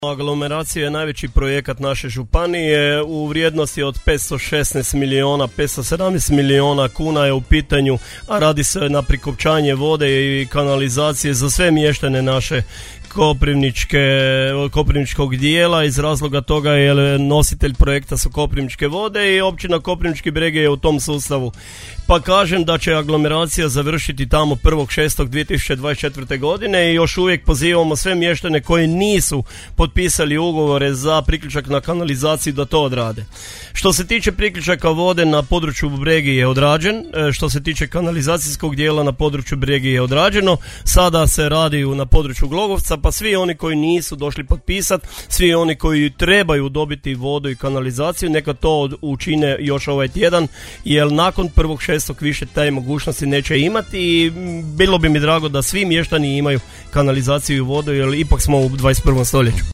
Načelnik Općine Koprivnički Bregi Mato Kuzminski bio gost emisije Susjedne općine - Podravski radio | 87,6 MHz